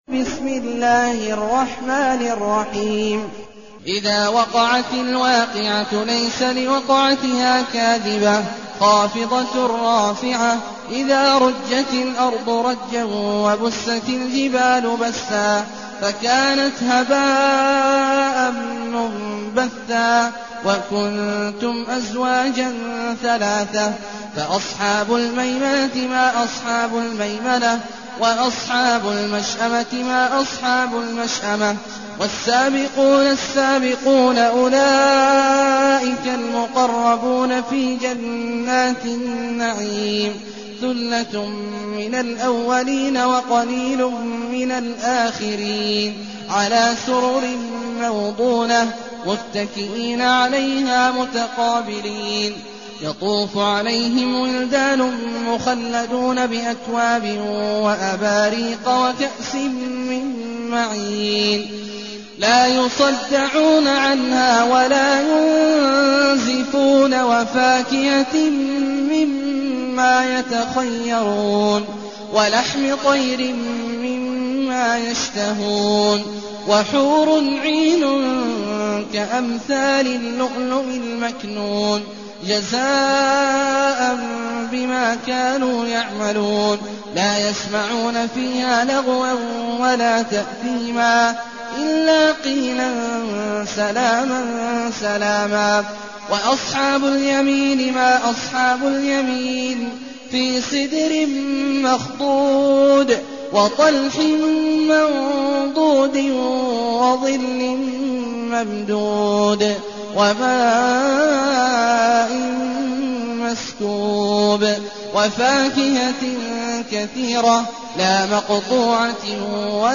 المكان: المسجد الحرام الشيخ: عبد الله عواد الجهني عبد الله عواد الجهني الواقعة